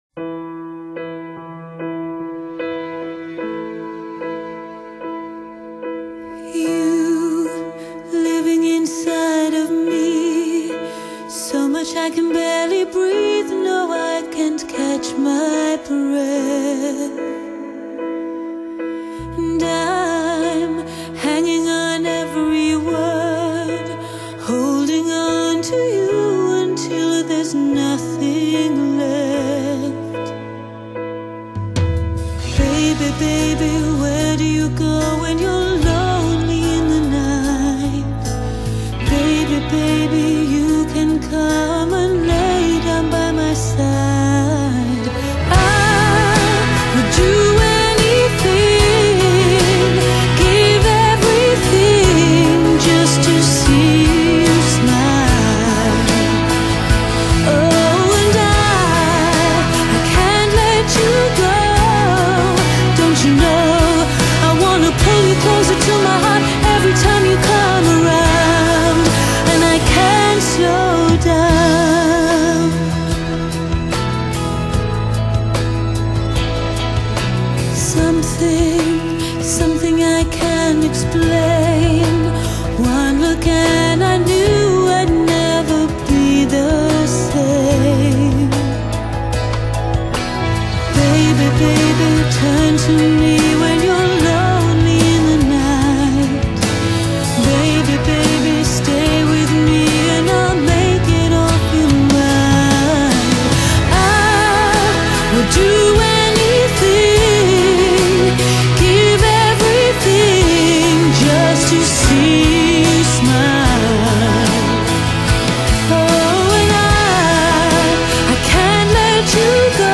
Genre: Classical, Pop, Vocal